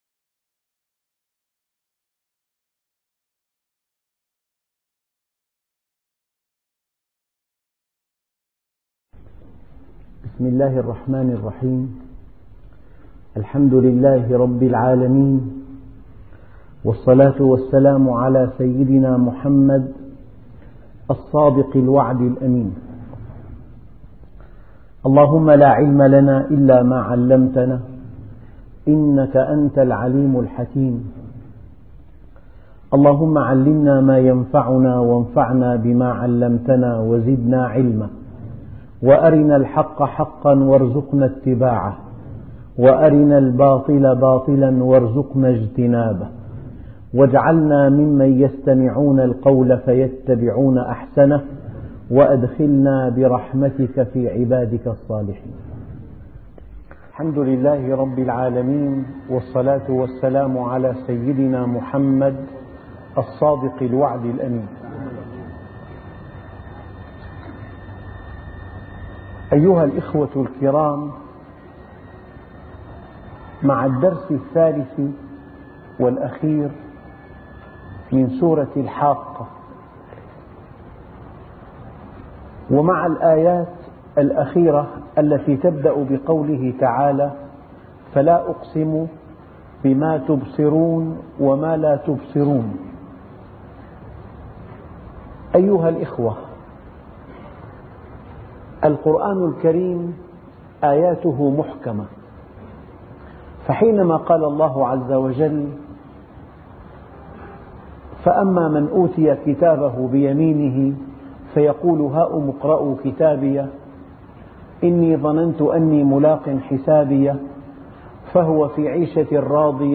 أرشيف الإسلام - ~ أرشيف صوتي لدروس وخطب ومحاضرات د. محمد راتب النابلسي